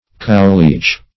Search Result for " cowleech" : The Collaborative International Dictionary of English v.0.48: Cowleech \Cow"leech`\ (kou"l?ch`), n. [2d cow + leech a physician.] One who heals diseases of cows; a cow doctor.